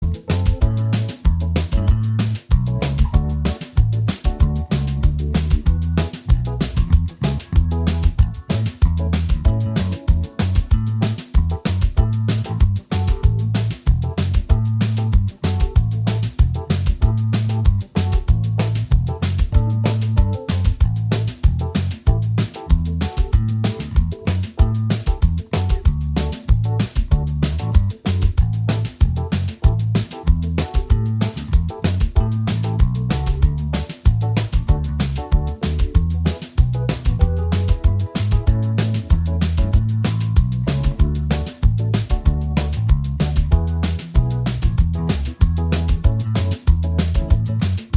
Minus All Guitars Soft Rock 3:48 Buy £1.50